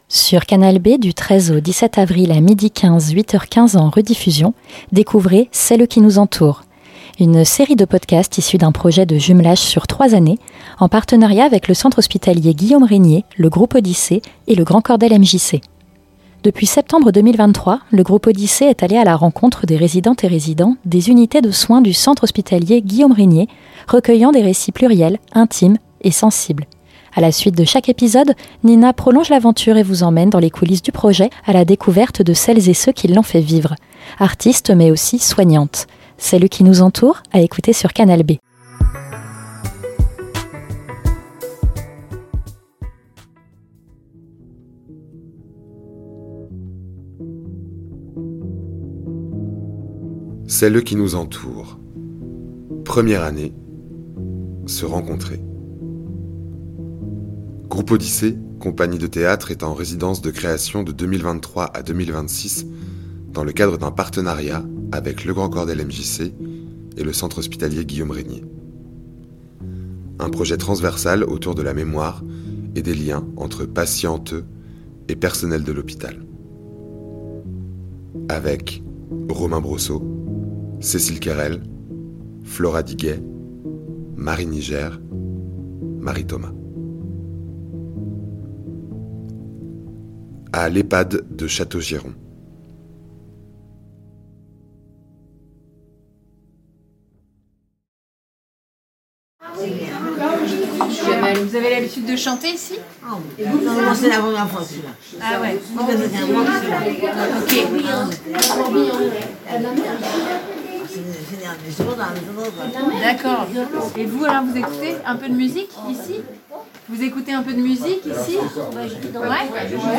Restitution sonore
C'est à l'EHPAD de Châteaugiron que ce projet pose ses valises pour le deuxième épisode d'une série de cinq restitutions sonores. Interview A la suite de chaque épisode